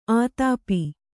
♪ ātāpi